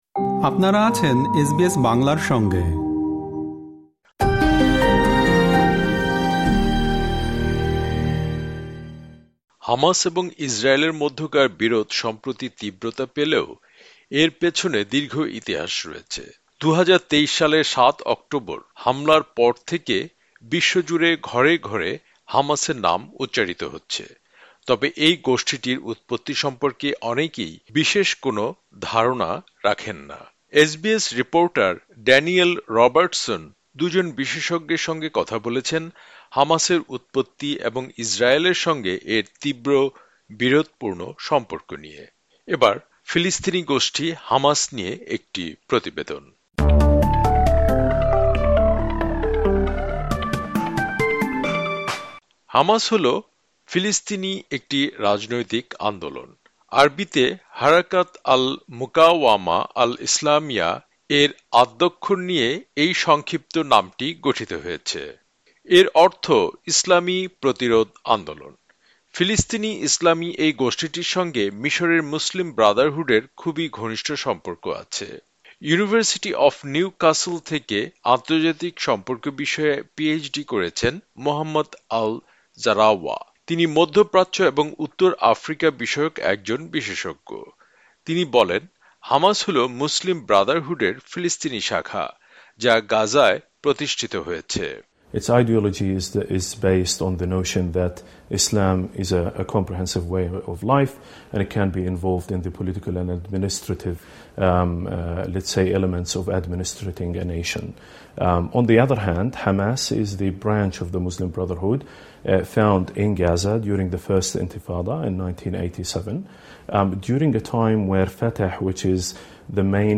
- হামাস নিয়ে একটি প্রতিবেদন।